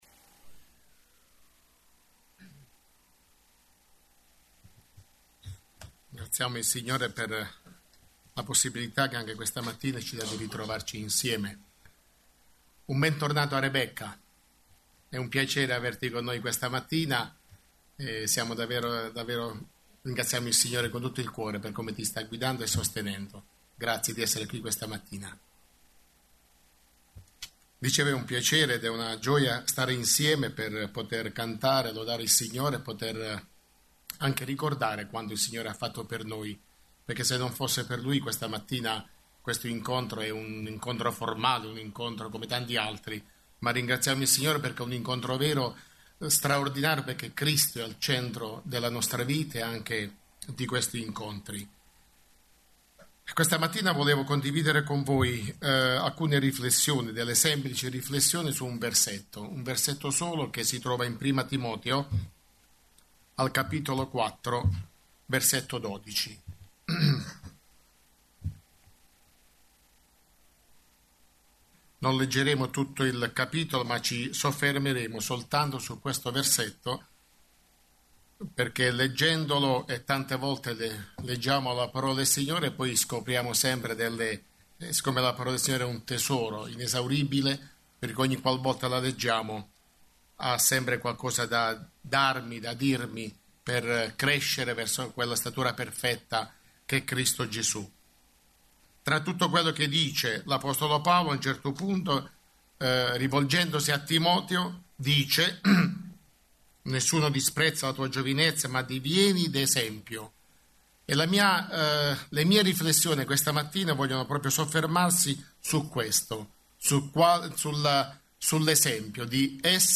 Bible Text: 2 Timoteo 4:12 | Preacher